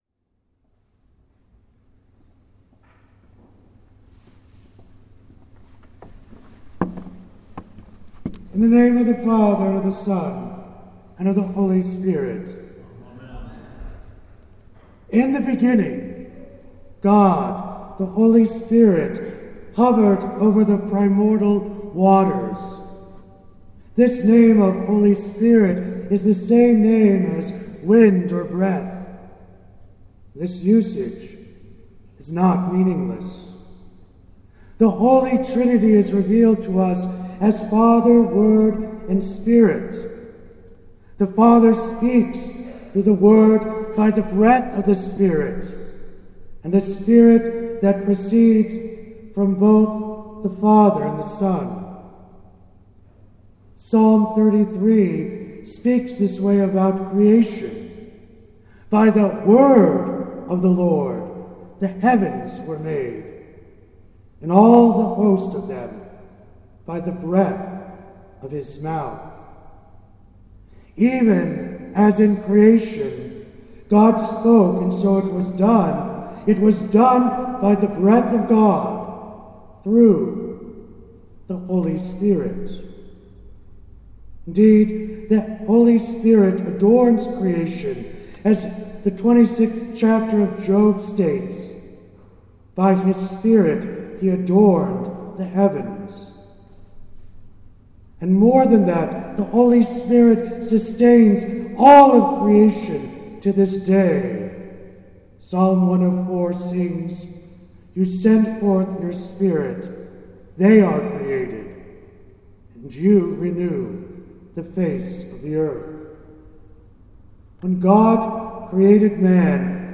Easter Vigil